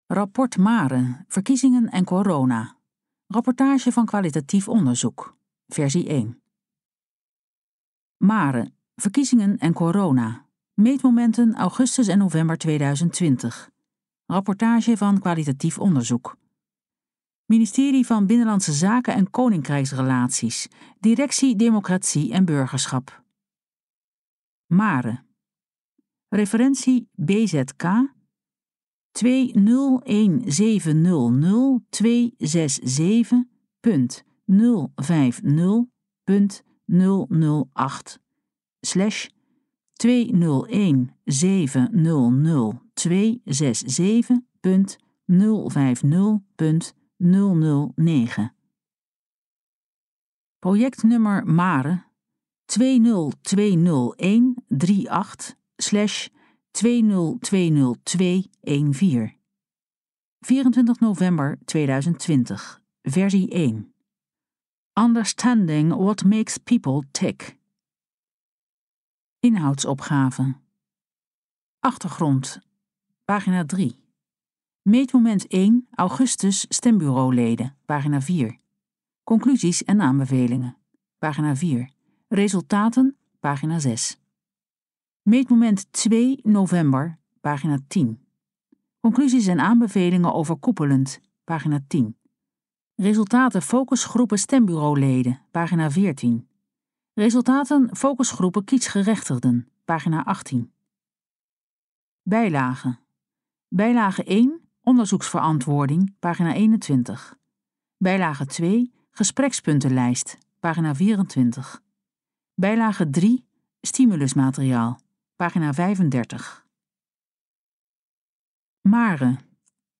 Verkiezingen en corona (PDF | 39 pagina's | 3,0 MB) Gesproken versie van het rapport Verkiezingen en corona.